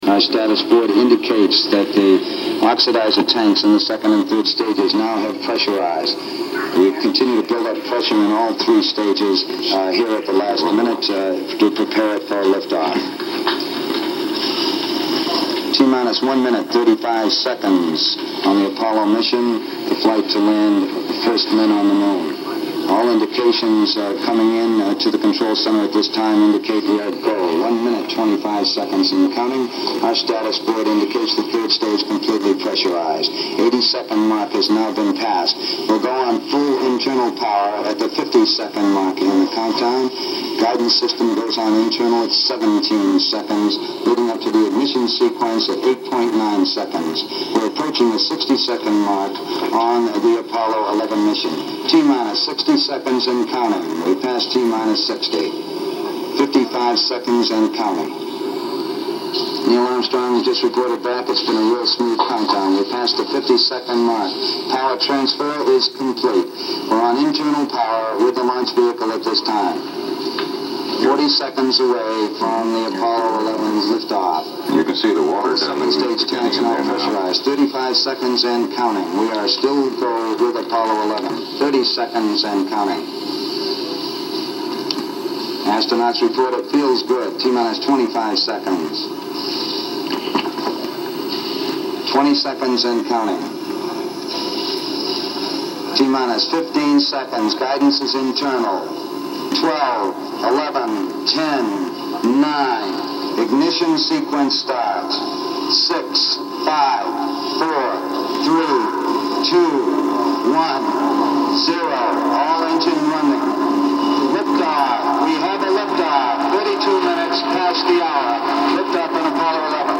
On this day, 50 years ago, NASA launched APOLLO 11 and put the first man on the moon. The following is audio beginning at one minute and thirty seconds to launch.